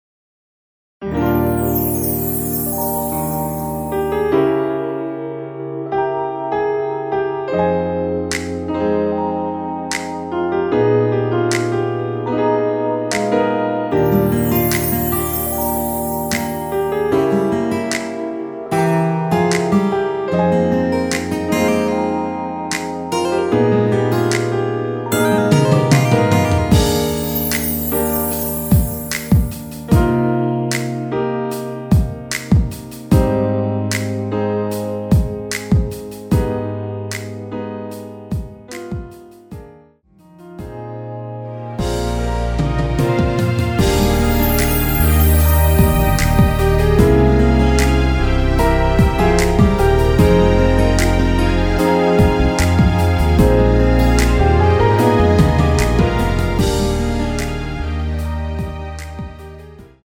원키에서 (-1)내린 MR 입니다.
Eb
앞부분30초, 뒷부분30초씩 편집해서 올려 드리고 있습니다.
중간에 음이 끈어지고 다시 나오는 이유는